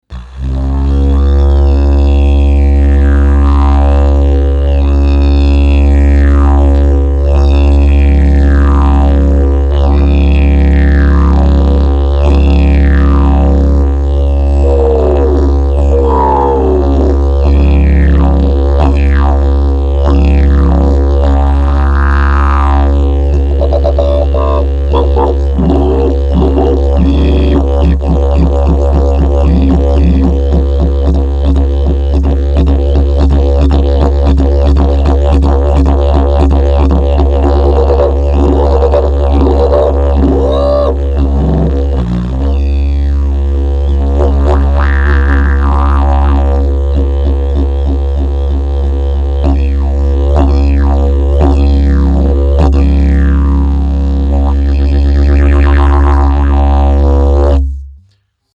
A super rare Bloodwood Collectors didgeridoo!
Wood type: Bloodwood
Musical key: C#
Overtones: F, C#
Categories: Bare Natural, Collectors, Healer / Boomer, Highest Quality Instrument, Highly Resonant and Responsive, Sun Seasoned